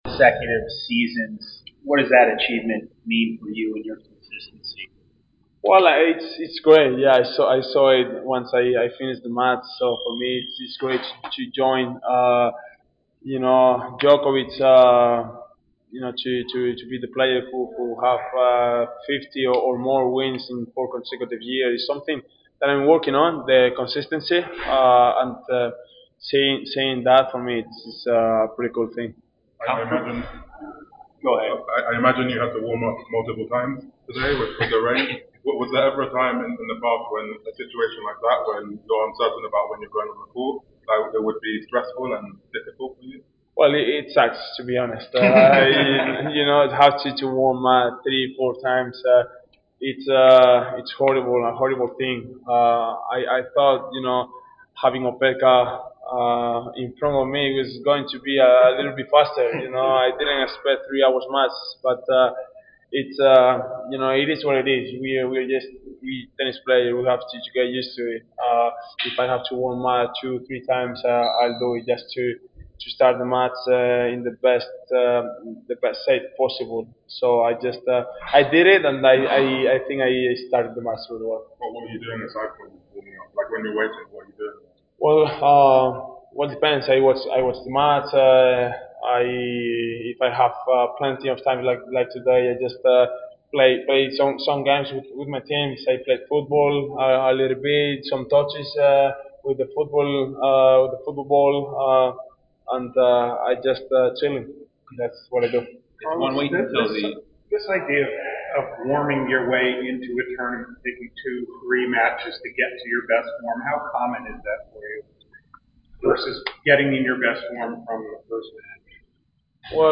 Carlos Alcaraz post-match interview after defeating Hamad Medjedovic 6-4. 6-4 in the 3rd Round of the Cincinnati Open.